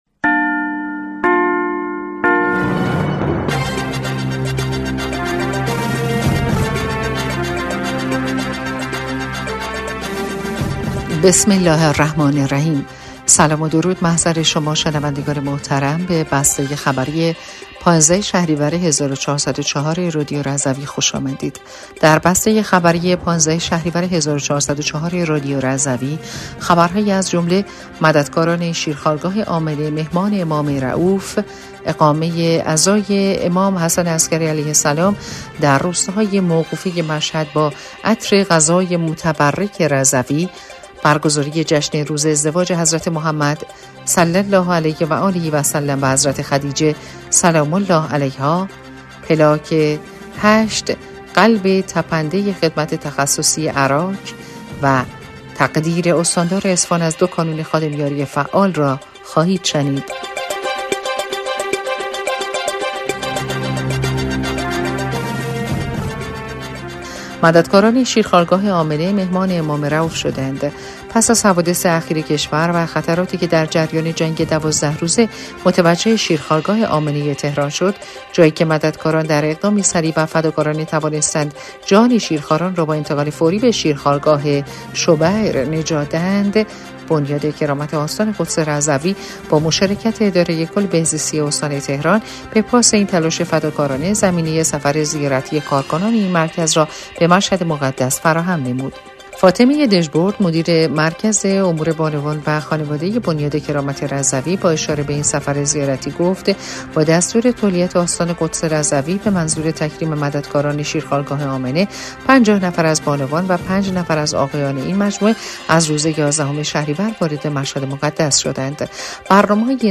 بسته خبری ۱۵ شهریور ۱۴۰۴ رادیو رضوی/